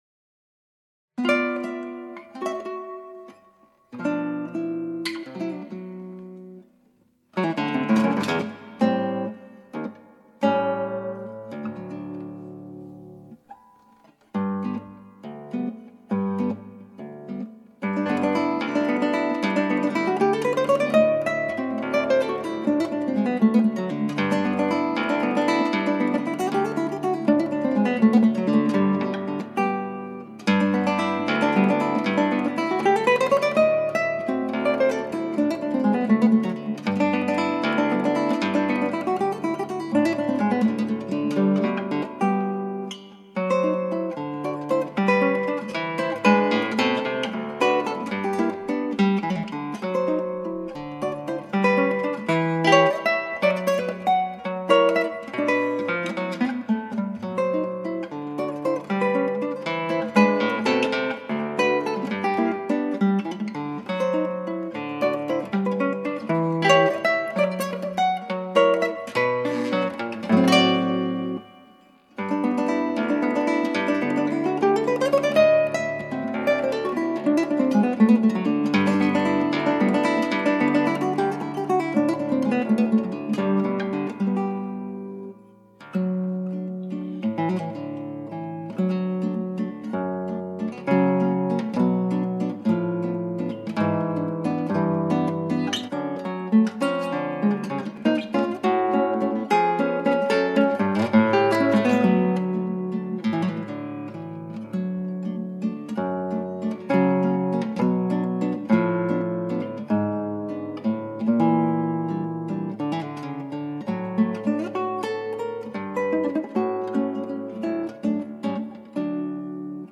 クラシックギター　ストリーミング　コンサート
ギターっぽくない、ショパンみたいな曲調です。
ワルツってリズム難しいよ。